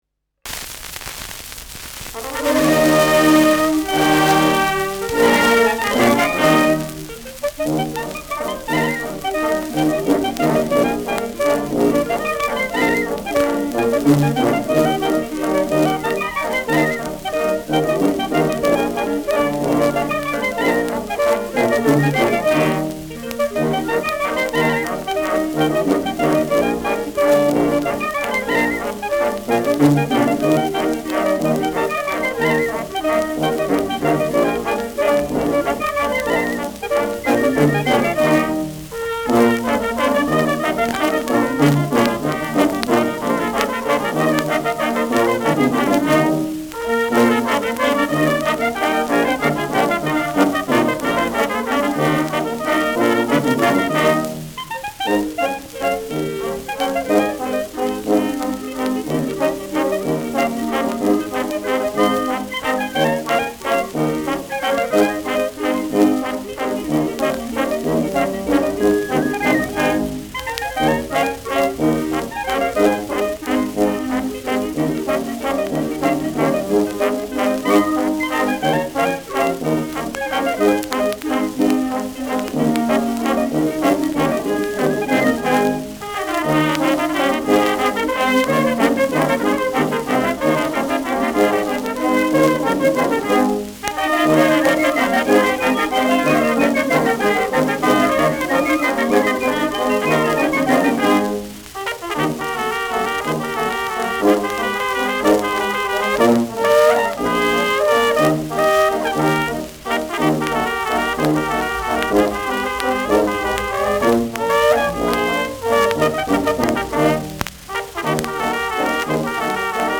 Schellackplatte
präsentes Rauschen : leichtes Knistern : abgespielt : vereinzeltes Knacken : gelegentliches Nadelgeräusch
Kapelle Lang, Nürnberg (Interpretation)
[Nürnberg] (Aufnahmeort)